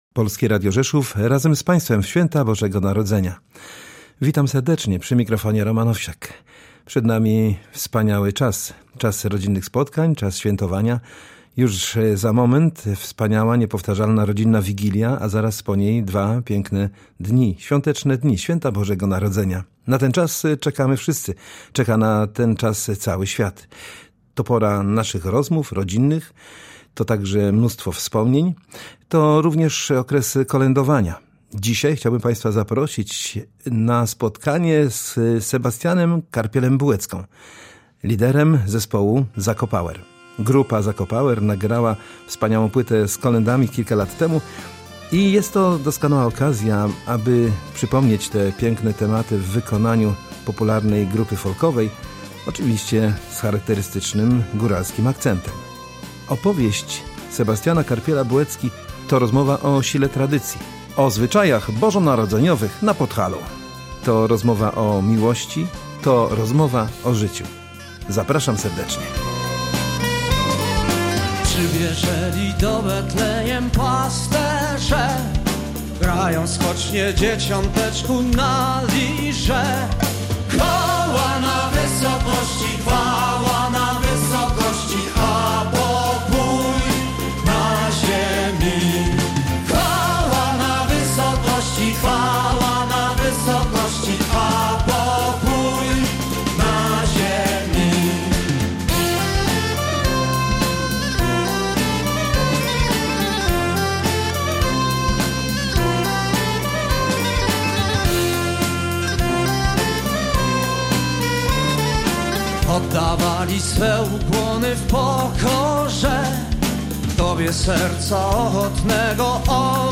Zapraszamy do wspólnego kolędowania z zespołem Zakopower i Polskim Radiem Rzeszów.